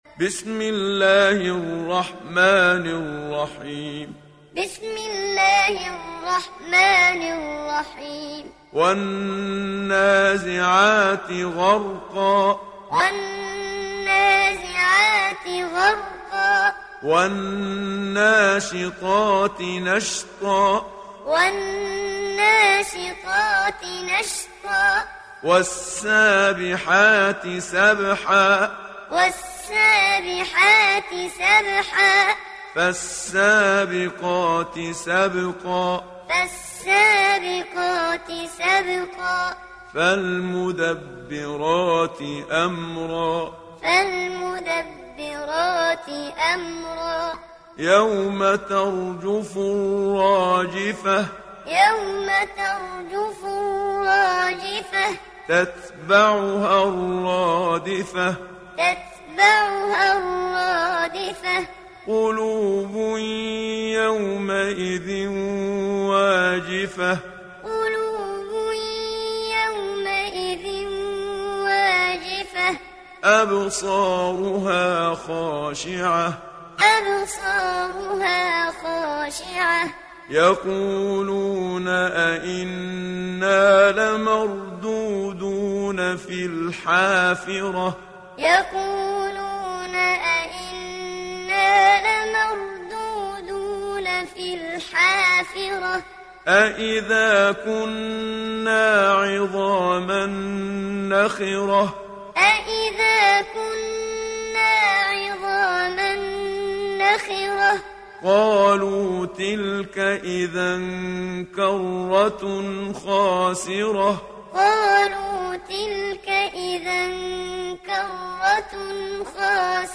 دانلود سوره النازعات محمد صديق المنشاوي معلم